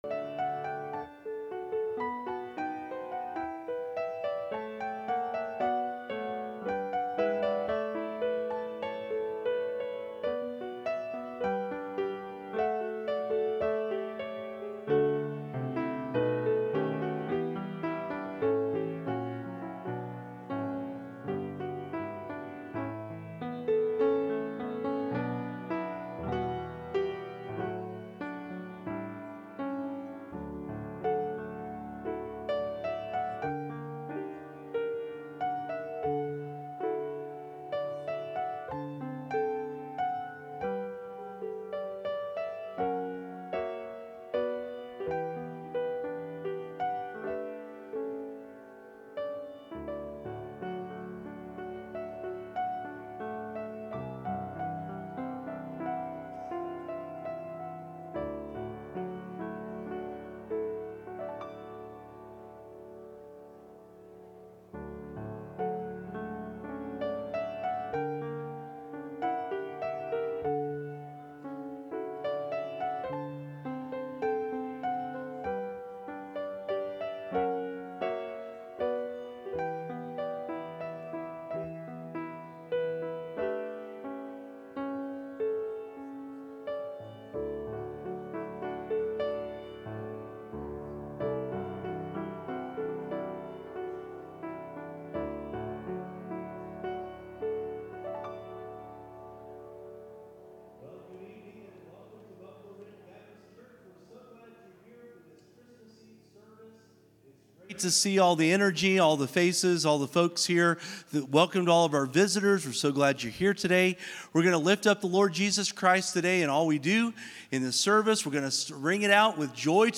12-24-25 Candlelight Service | Buffalo Ridge Baptist Church